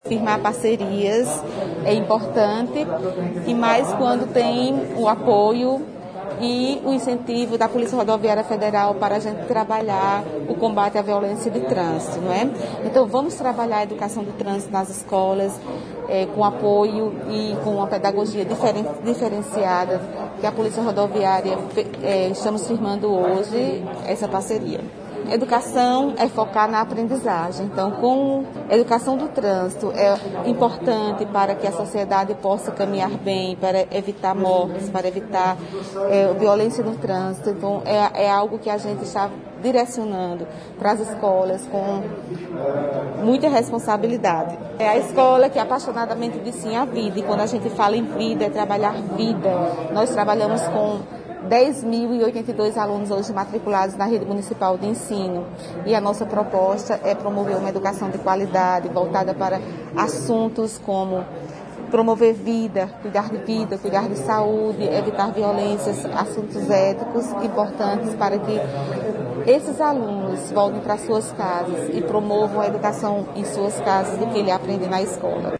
Aconteceu na manhã desta sexta-feira (26) na sede da Delegacia da PRF Patos a assinatura de um Termo de Adesão e Compromisso entre a Prefeitura de Patos, Polícia Rodoviária Federal e Secretaria Municipal de Educação, ao Projeto EDUCAR PRF.